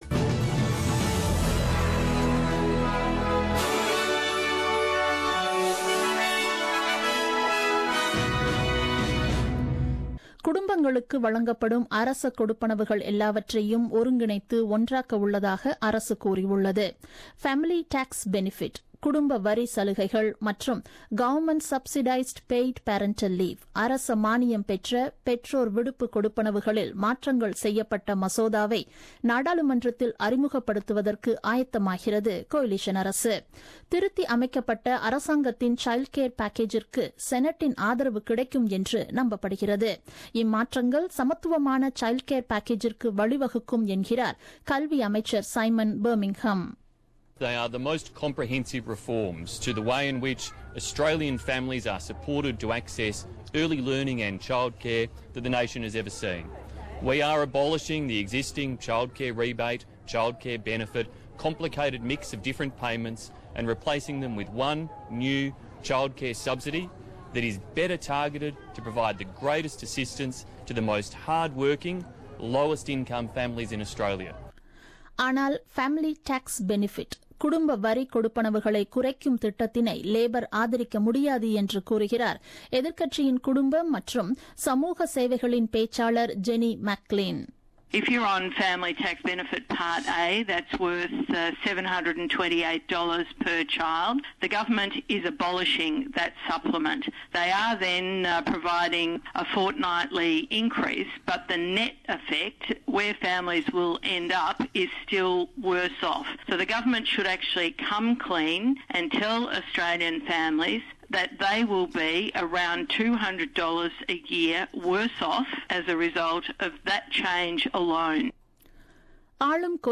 The news bulletin broadcasted on 08 Feb 2017 at 8pm.